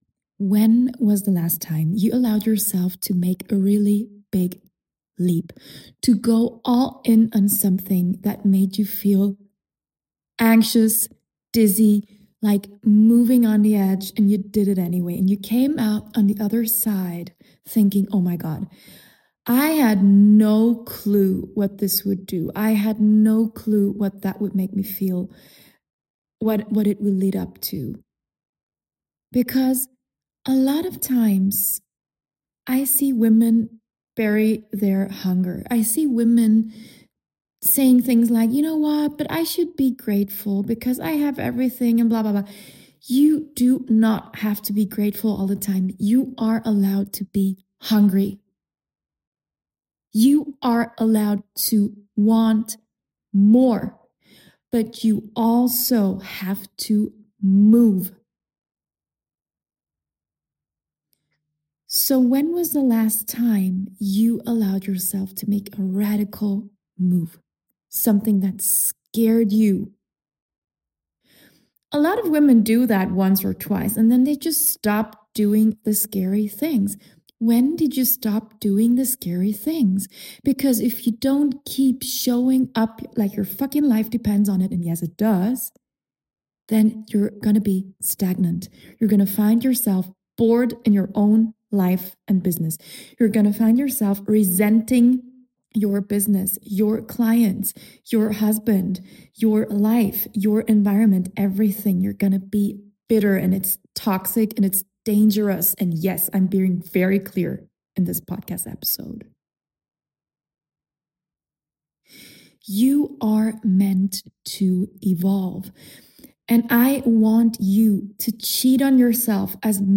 This short, spontaneous, and energetic episode is a reminder that playing small will not keep you safe, and the cure for your frustration is to allow yourself to play 10x bigger ... now 🤌